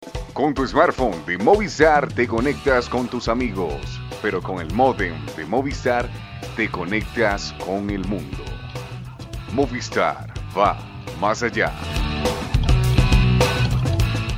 Voz grave, ideal para radio y locuciones que impliquen acento neutro y seriedad.
Sprechprobe: Werbung (Muttersprache):
serious voice, serious, neutral accent. Dubbing, handling of nuances. Voice of radio announcer.